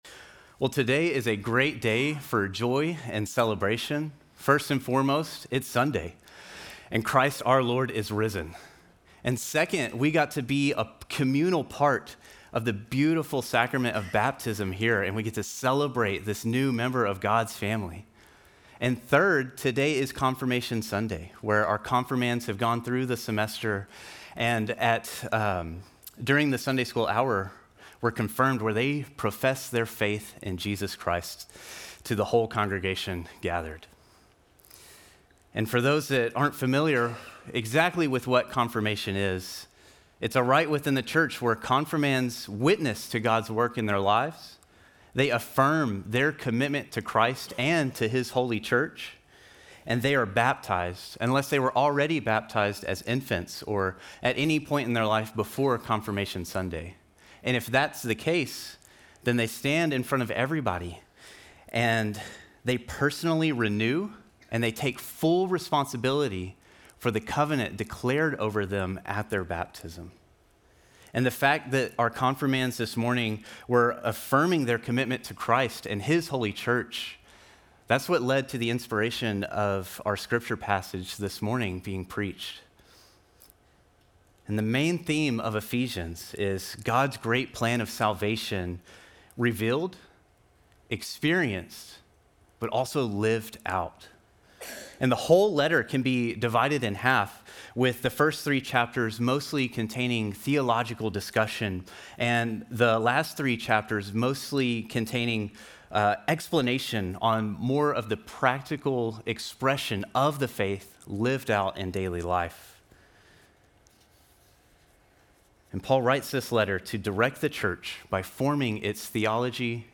Sermon text: Ephesians 2:17-22